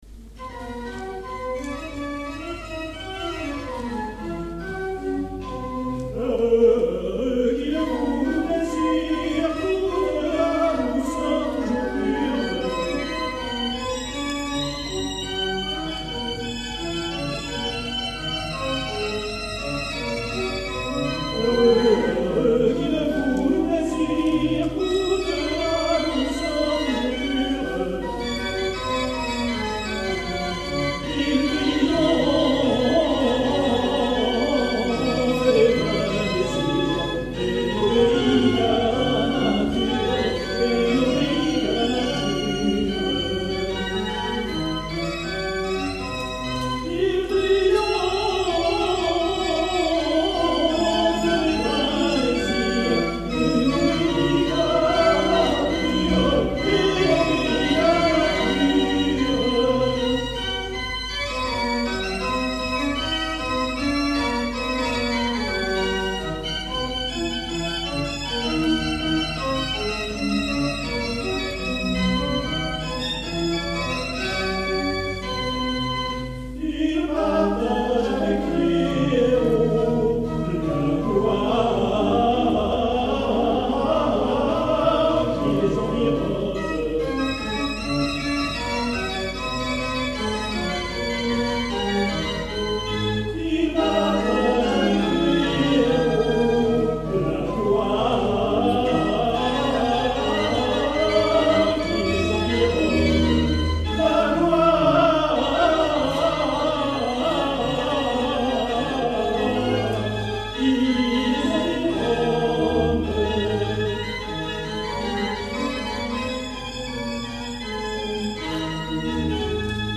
Concert "Ménétris"&"Saltarelle" dimanche 11 février 1979 Eglise du Bourg CHARNAY-LES-MACON
Extaits du Concert de Charnay